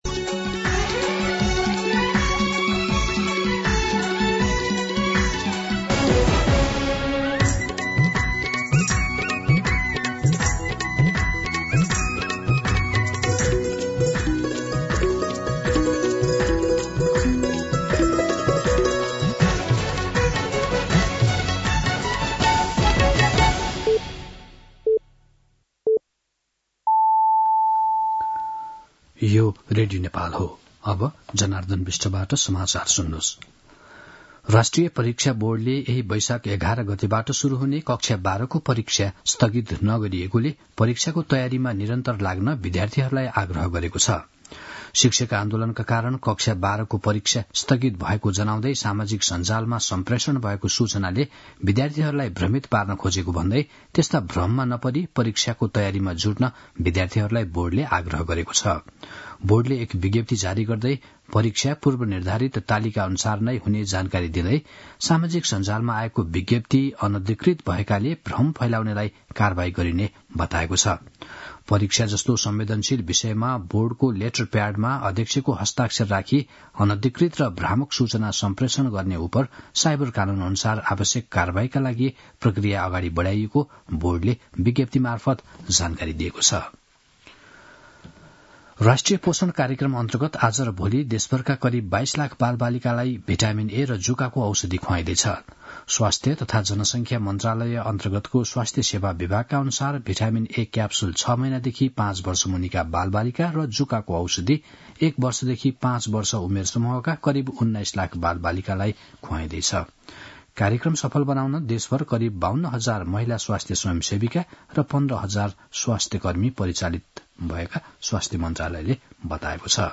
दिउँसो १ बजेको नेपाली समाचार : ६ वैशाख , २०८२
1-pm-Nepali-News-5.mp3